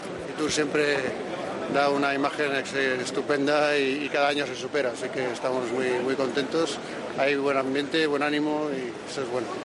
La inauguración no ha contado con ninguna intervención pública de don Felipe, como es habitual, pero, al acercarse a la zona de trabajo de TVE en la feria, ha respondido brevemente a unas preguntas de esta cadena y se ha mostrado "muy contento" por la "imagen estupenda" y el "buen ambiente" que transmite siempre Fitur, que, según ha precisado, "cada año se supera".